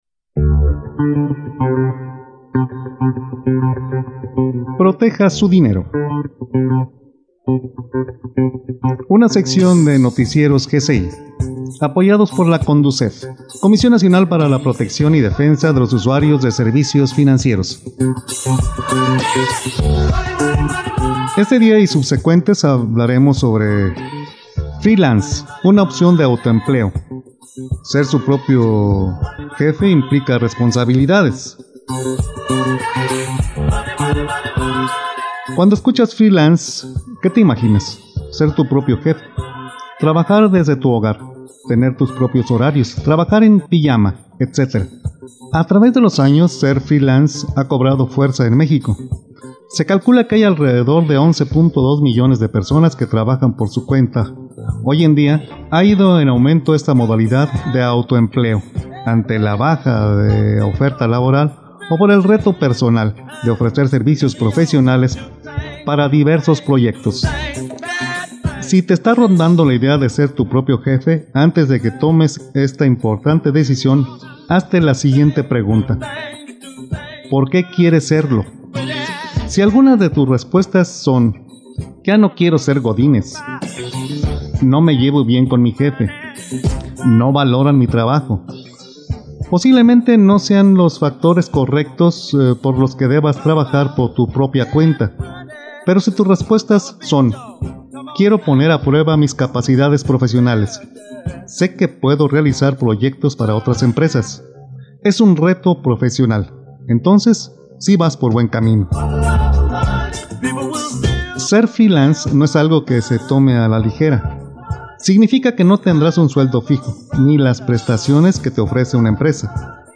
CLIMA: con el Sistema Meteorológico Nacional.
NACIONALES